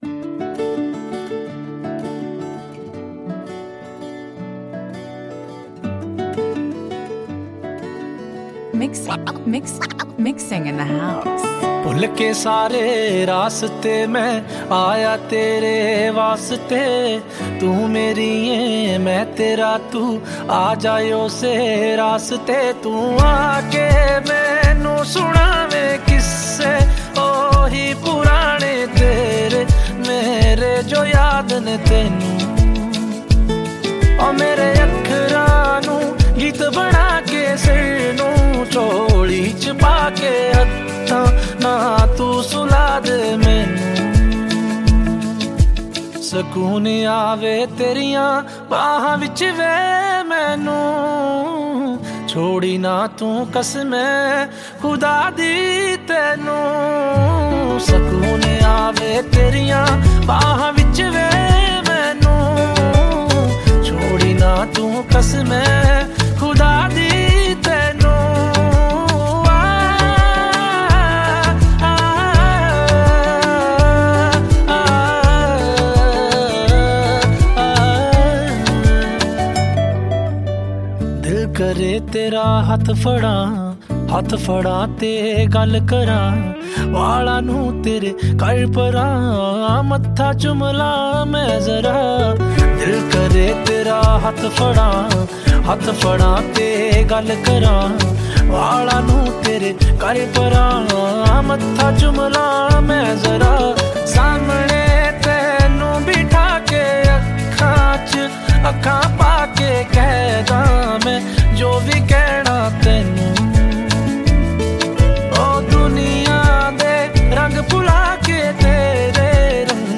Releted Files Of Punjabi Music